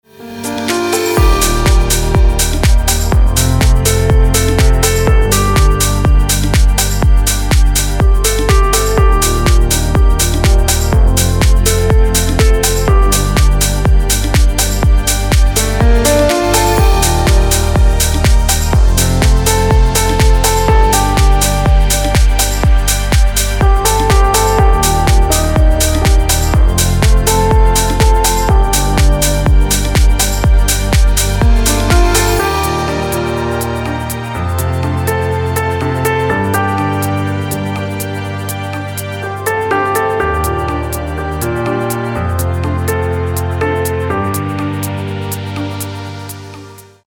• Качество: 320, Stereo
красивые
спокойные
без слов
клавишные
инструментальные
пианино